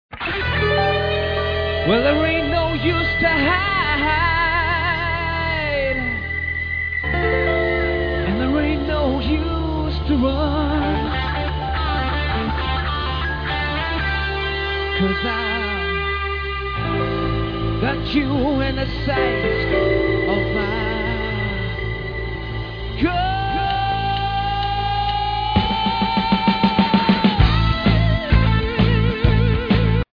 Rock 選択してください